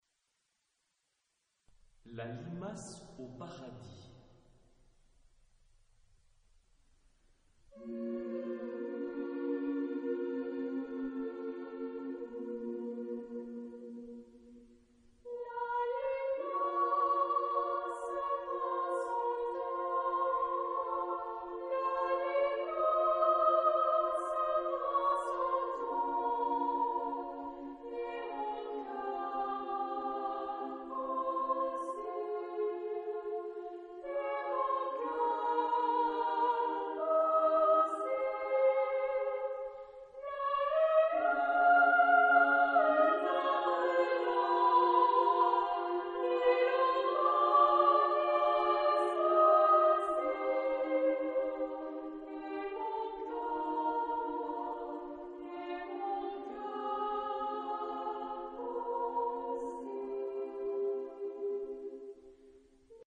Genre-Style-Forme : Profane ; Chanson
Caractère de la pièce : andantino
Type de choeur : SA  (2 voix égales )
Solistes : Soprano (1)  (1 soliste(s))
Tonalité : si bémol mineur